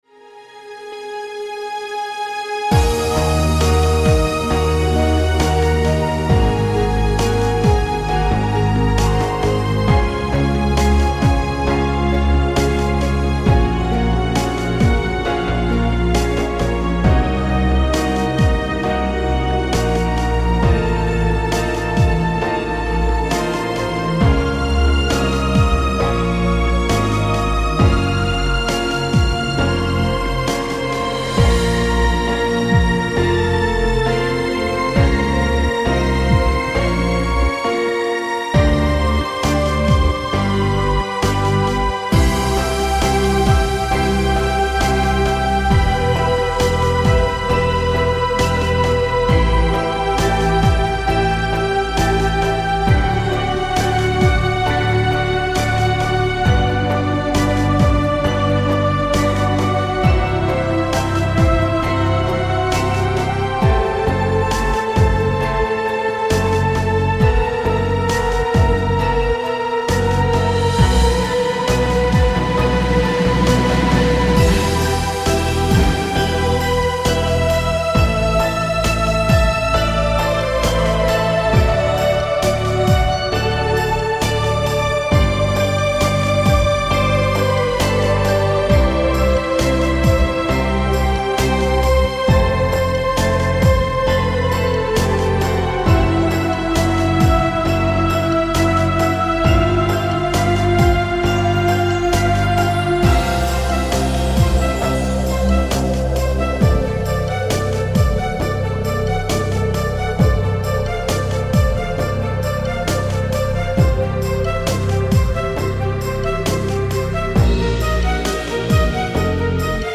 Пример написания минуса на заказ.